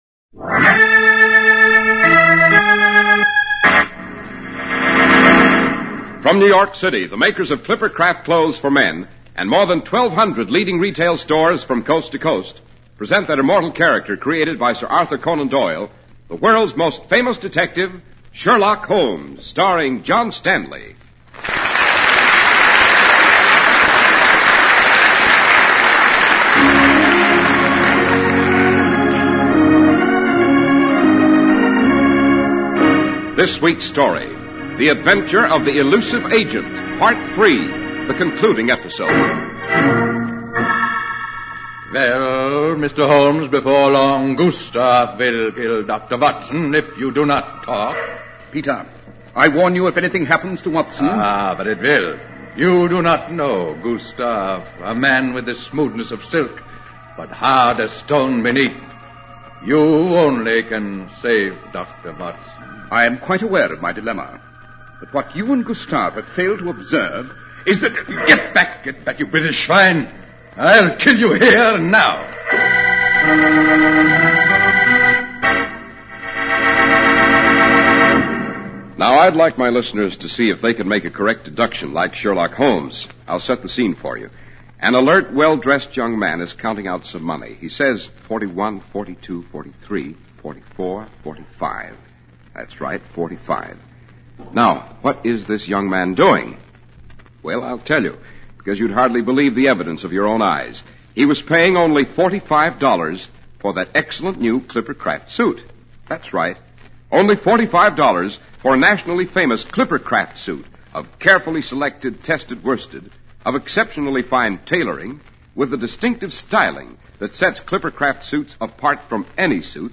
Radio Show Drama with Sherlock Holmes - The Elusive Agent 3 1949